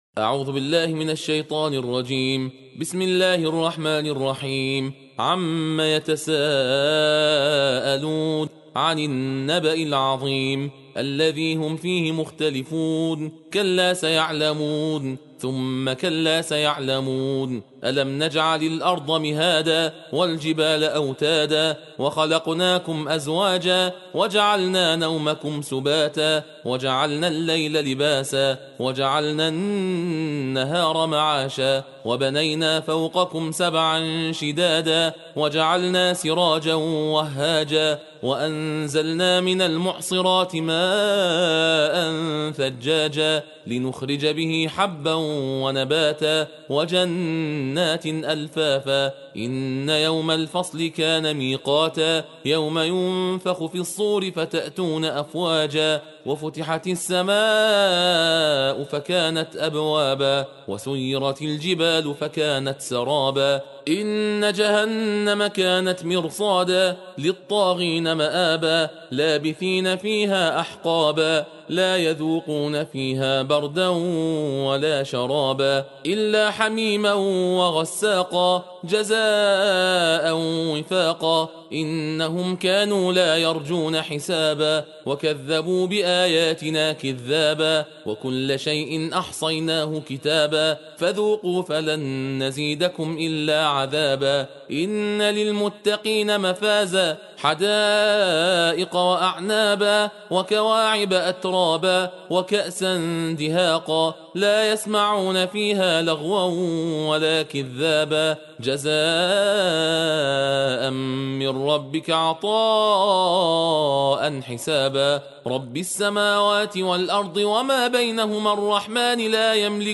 جزء سی ام قرآن صوتی تندخوانی با متن و ترجمه درشت جز 30 - ستاره
جزء ۳۰ قران از کجا تا کجاست و سوره های جزء سی أَمِ قرآن کدام‌اند؟ در این مطلب پاسخ داده‌ایم و فایل صوتی تندخوانی جز 30 با صدای دو قاری عزیز را هم برای‌تان آورده‌ایم.